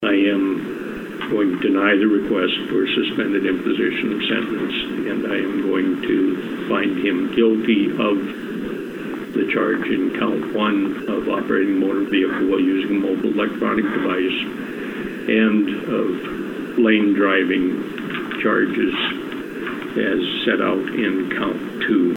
Judge Brown announces fines – AUDIO COURTESY KELOLAND TELEVISION